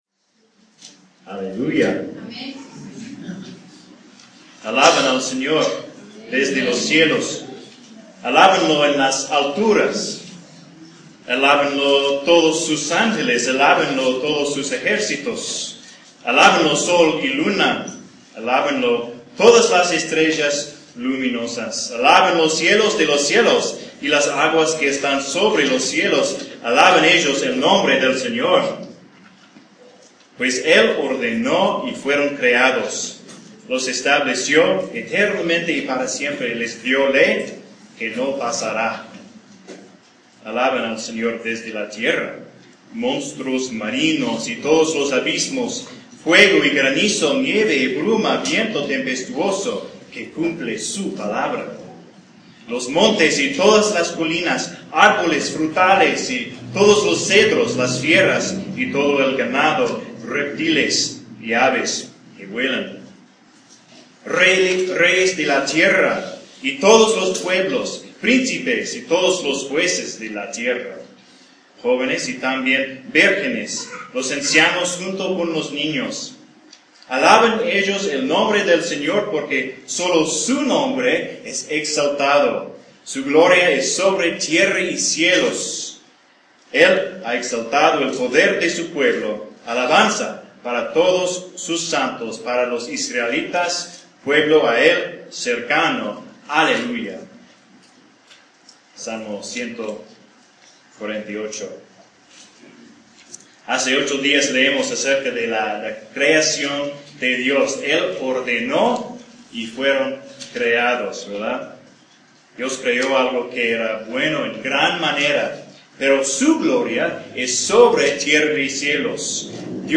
Un sermón de Génesis 3 – desde el Temor hasta el Trono.